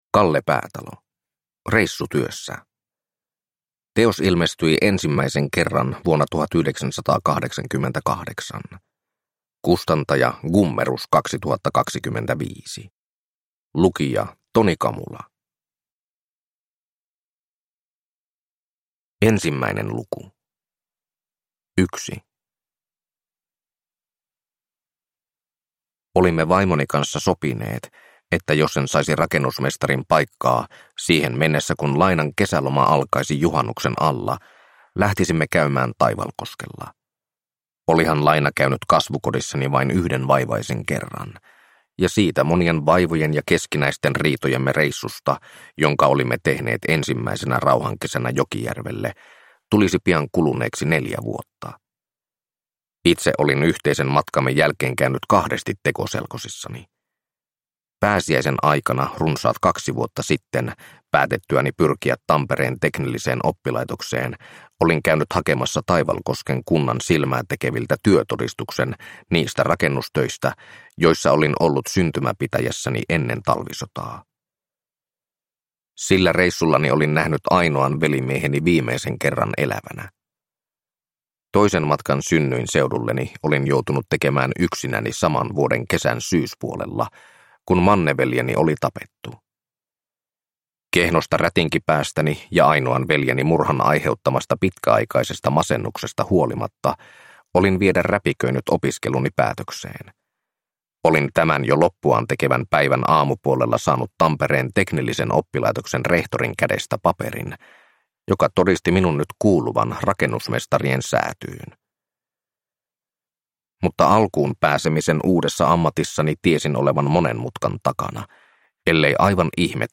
Reissutyössä (ljudbok) av Kalle Päätalo